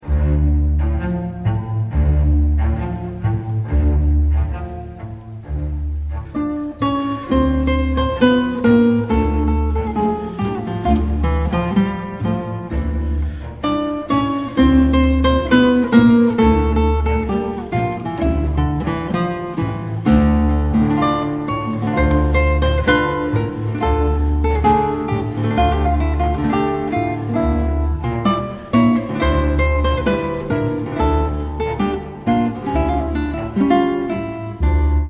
Отрывок из классической оперы
Категория: Классические рингтоны